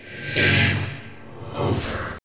game_over.ogg